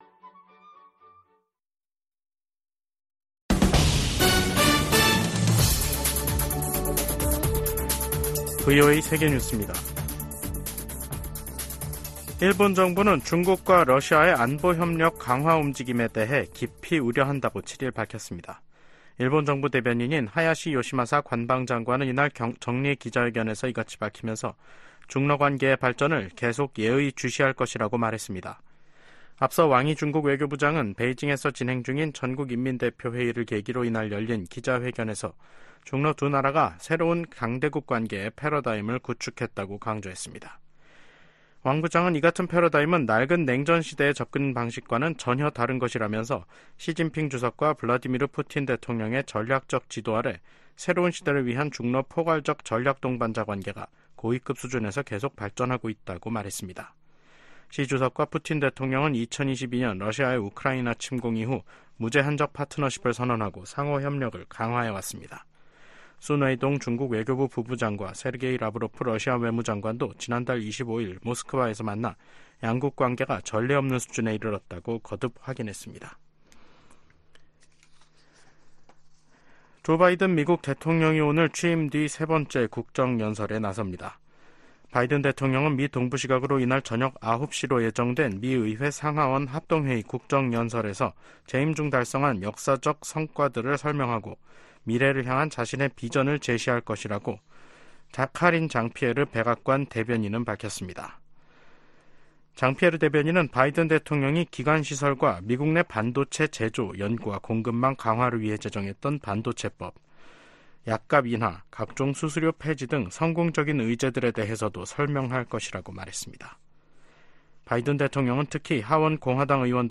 생방송 여기는 워싱턴입니다 2024/3/7 저녁